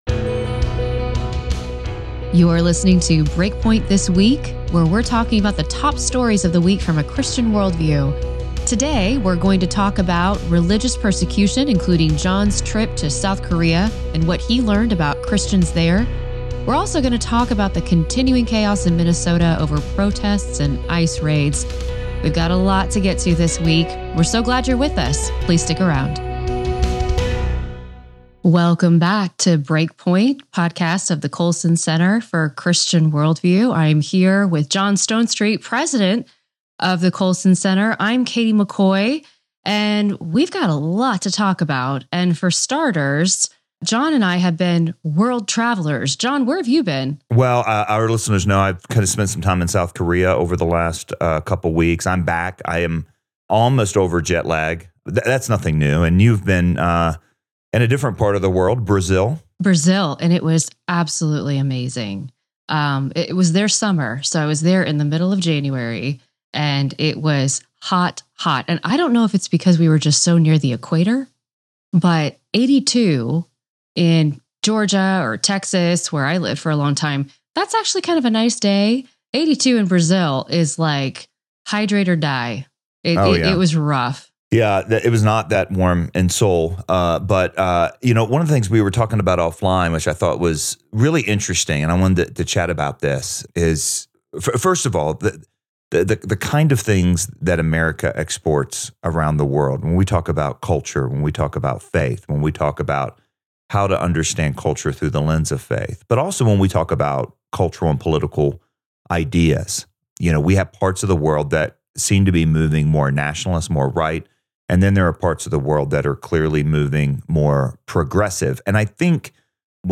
Guest host